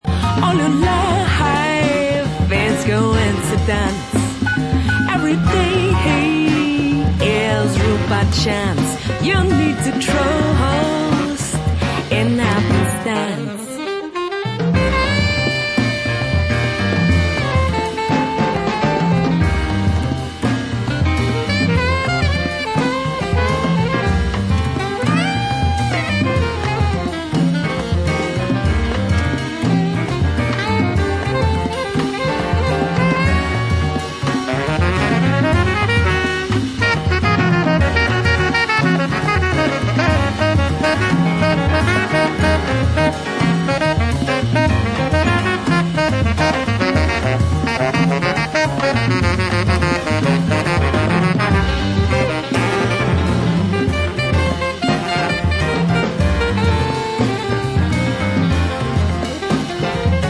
acoustic bass
strong groove led Spiritual Jazz